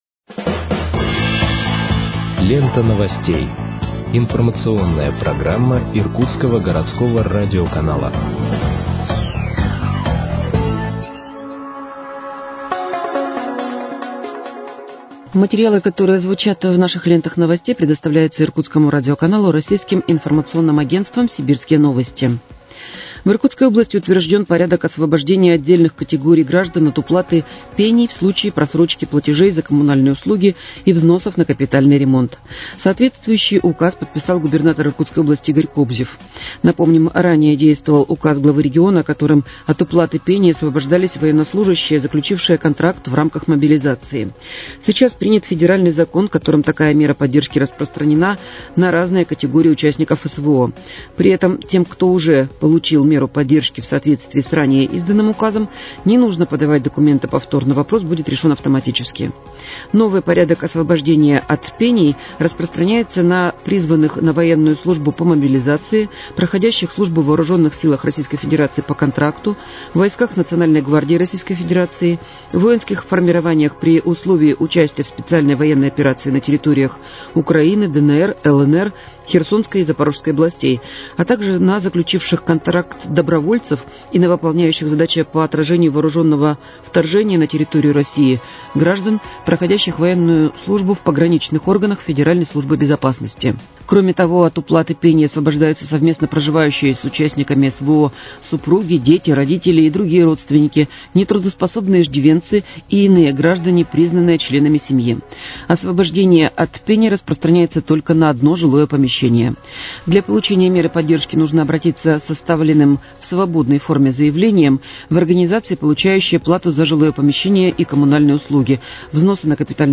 Выпуск новостей в подкастах газеты «Иркутск» от 12.09.2023 № 1